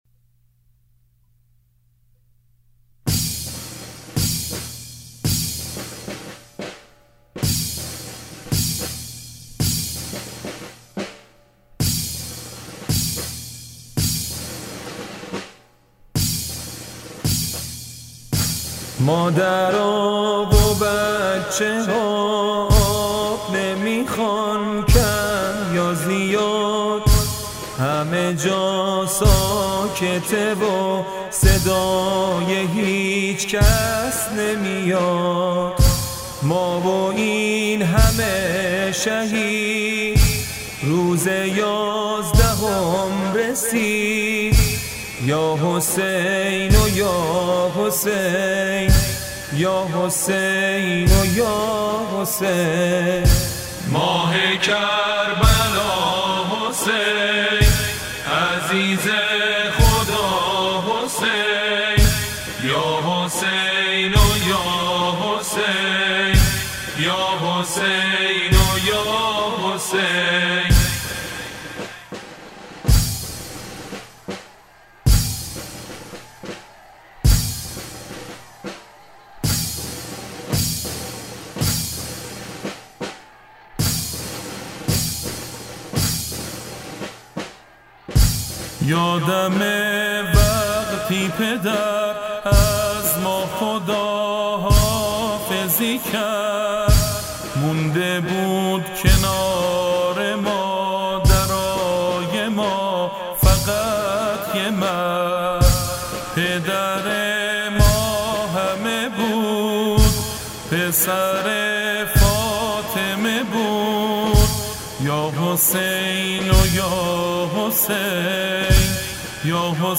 مجموعه شعر و نمونه‌خوانی زنجیر زنی / ۲
برچسب ها: تکیه شهر ، نوحه خوانی ، زنجیر زنی ، شعر عاشوریی